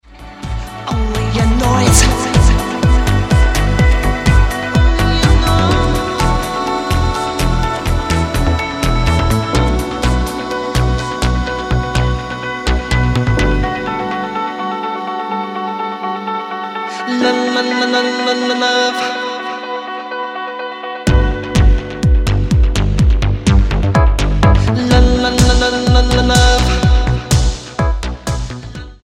Tremendous vocals and playing throughout